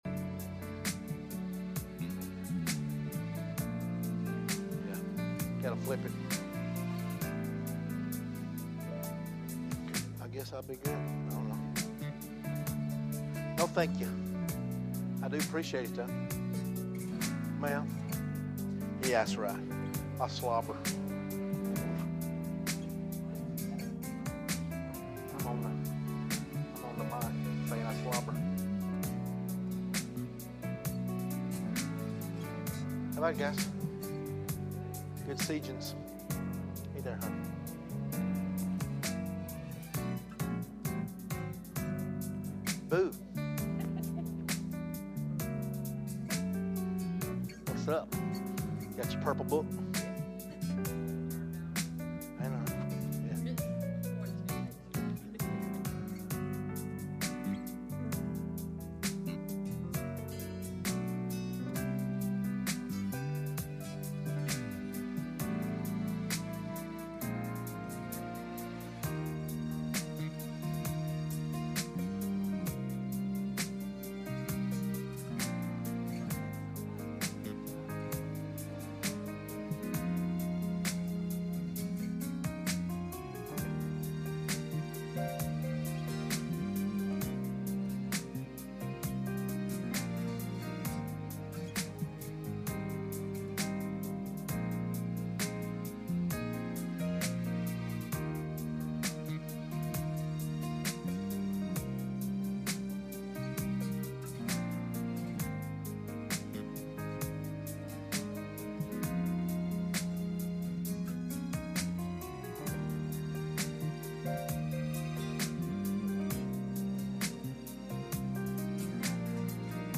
Sunday Night Service
Service Type: Sunday Evening